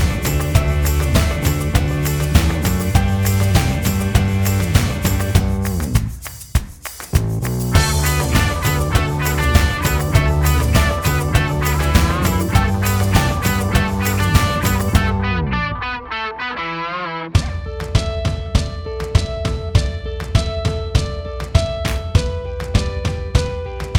Minus Lead Guitar Indie / Alternative 3:13 Buy £1.50